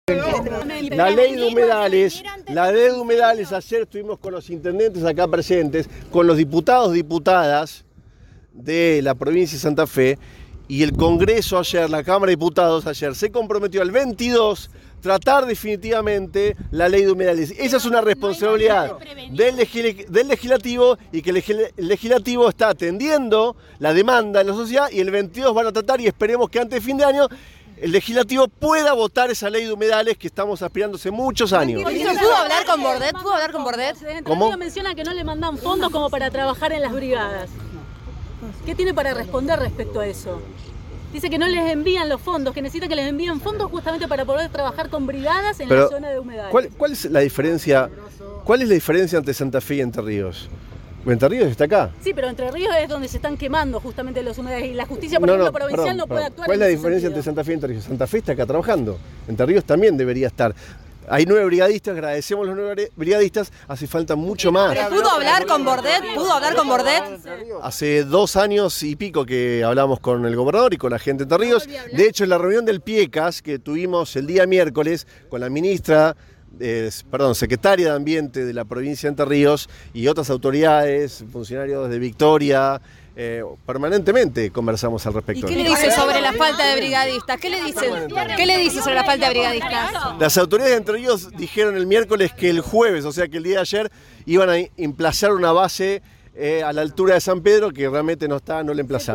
Juan Cabandié, ministro de Ambiente y Desarrollo Sostenible de la Nación, habló con el móvil de Cadena 3 Rosario, en Siempre Juntos, en la localidad de Alvear.